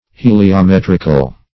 Search Result for " heliometrical" : The Collaborative International Dictionary of English v.0.48: Heliometric \He`li*o*met"ric\, Heliometrical \He`li*o*met"ric*al\, a. Of or pertaining to the heliometer, or to heliometry.